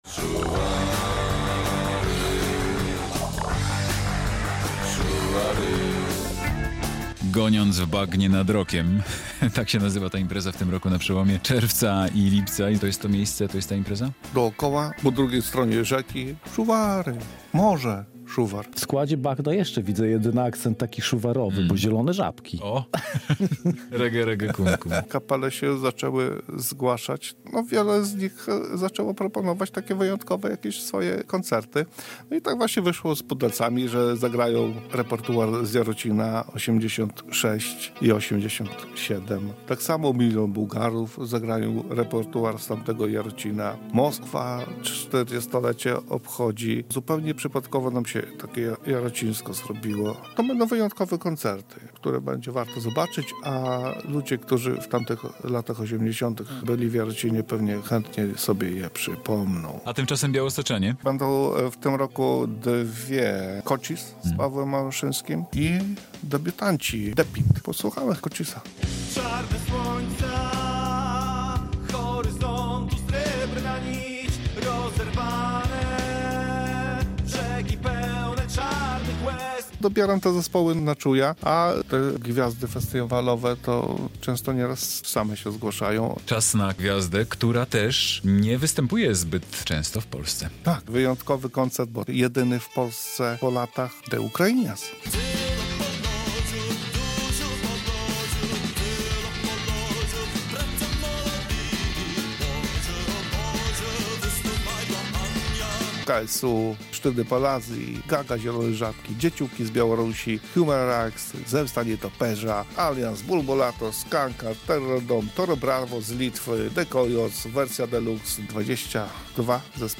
Radio Białystok | Magazyny | Podróże po kulturze | Zbliża się 13. edycja festiwalu "Rock na Bagnie"